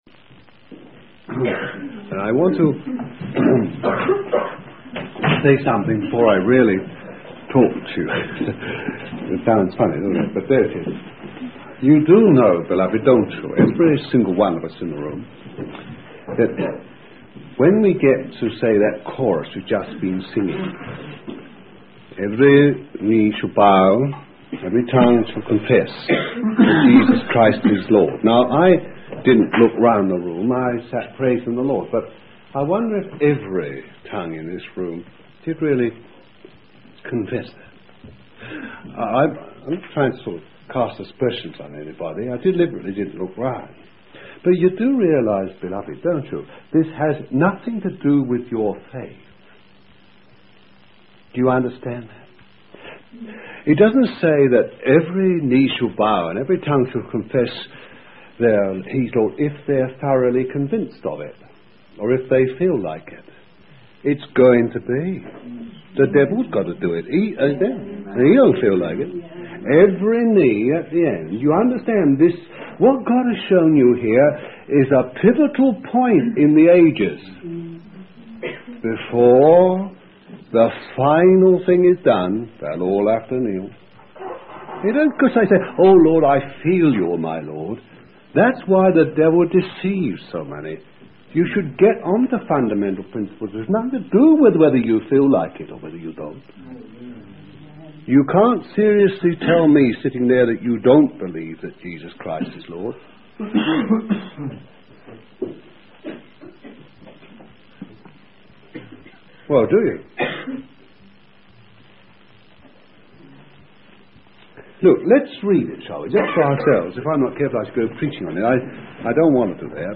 A testimony, Achenheath, Nov. 1975. Commonly called, "What Makes a Man Tick."